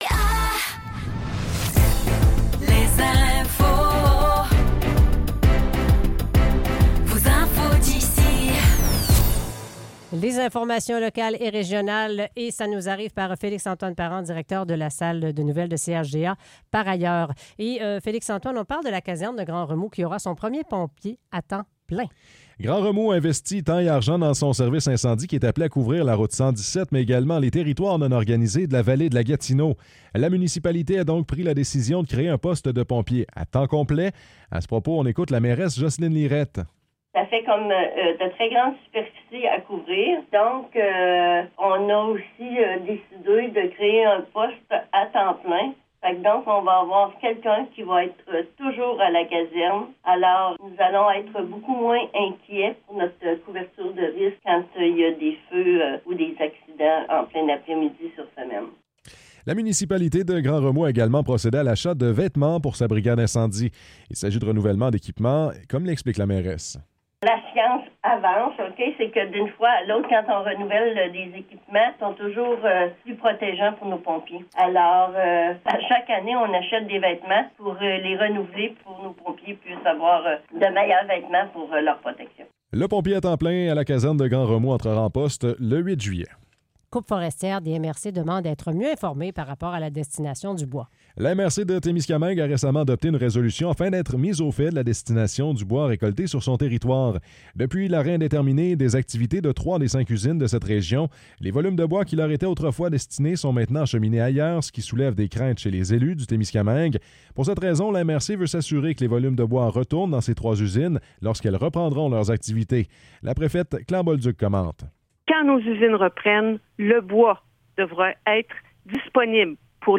Nouvelles locales - 2 juillet 2024 - 16 h